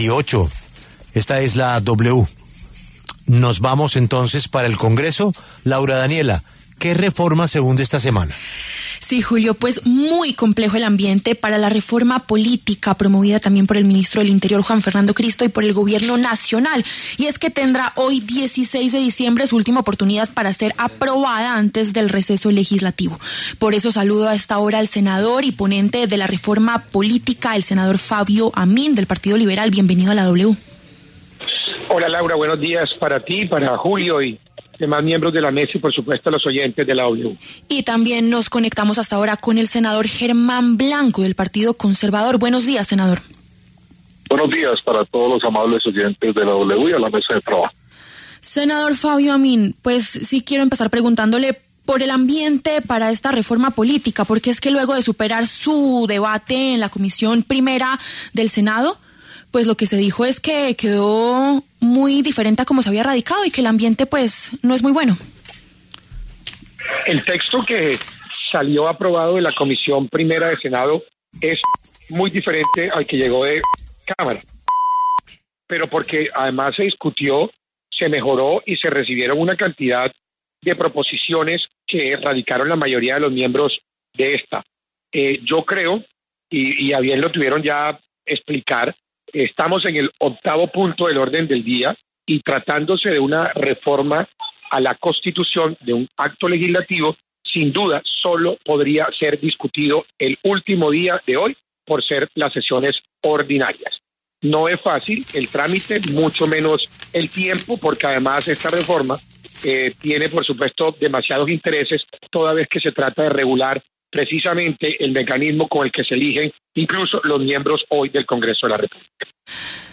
Los senadores Fabio Amín, del Partido Liberal, y German Blanco, del Partido Conservador, hablaron en los micrófonos de La W. Preocupa el transfuguismo y la eliminación de los límites para conformar coaliciones.